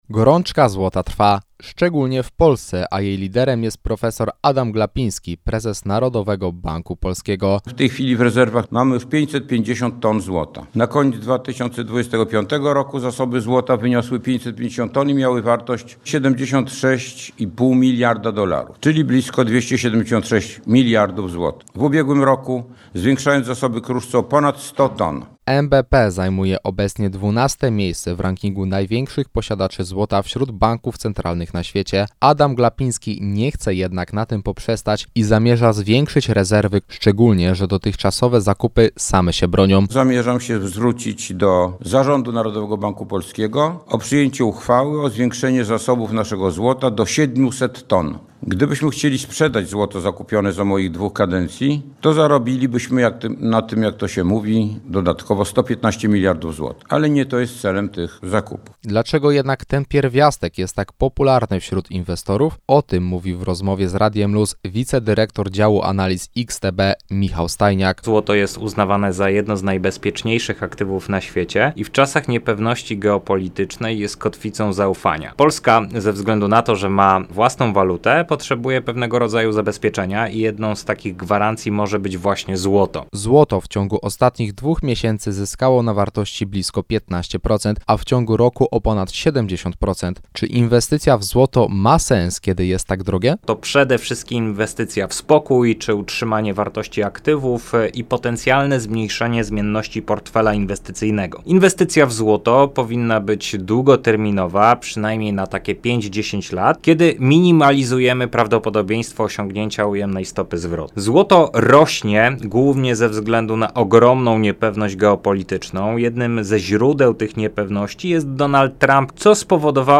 Prof. Adam Glapiński – prezes Narodowego Banku Polskiego podczas ostatniej konferencji prasowej ogłosił, że chce zwiększyć rezerwy złota do 700 ton.